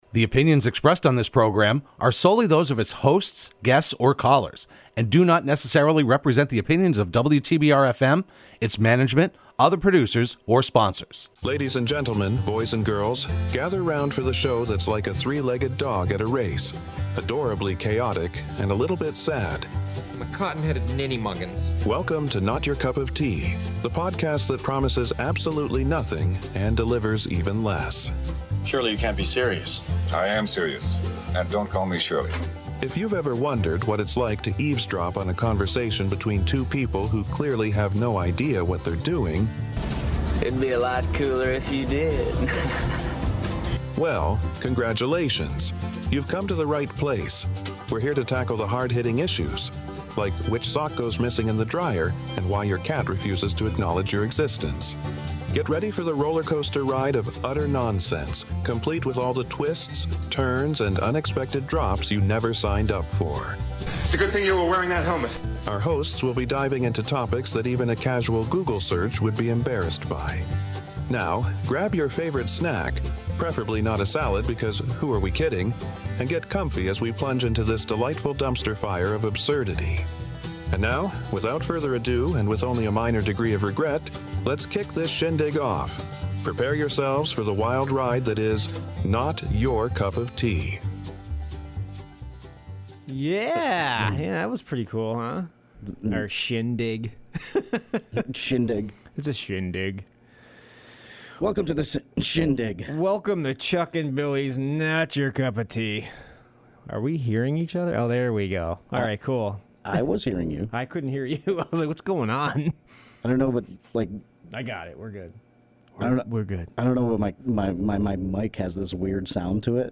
Broadcast live every Wednesday afternoon at 3:30pm on WTBR.